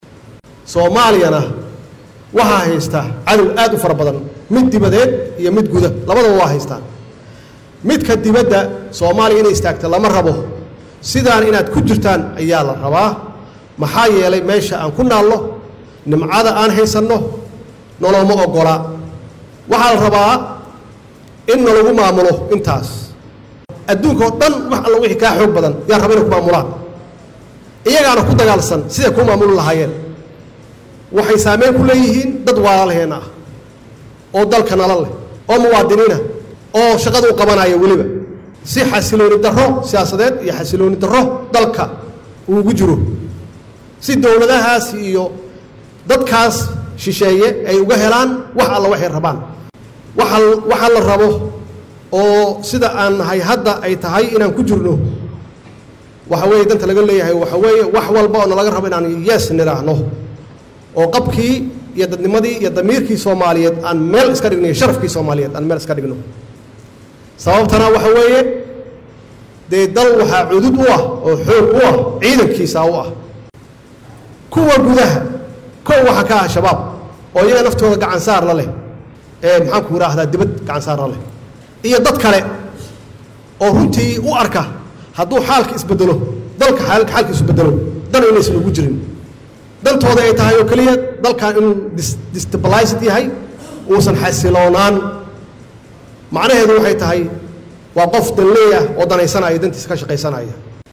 Muqdisho (Caasimada Online)-Madaxweynaha dowladda Soomaaliya Maxamed Cabdullaahi Farmaajo oo la hadlayay saraakiisha ciidamada xooga ayaa shaaciyay in muddo 22 Bilood ah oo u dhiganta sanad iyo 10 bilood in dowladiisa ay si toos ah u bixineysay mushaaraadka ciidamada.